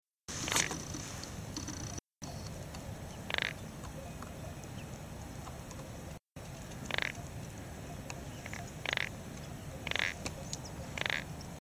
il Pelodite punteggiato
verso degli esemplari maschi è abbastanza particolare e viene emesso durante il periodo della riproduzione, fase in cui è possibile osservare con una certa facilità gli esemplari adulti, che invece trascorrono il resto della loro esistenza nascosti all'interno di muri a secco, in fessure nei tronchi di albero, sotto grandi massi o in altri luoghi appartati.
pelodite.mp3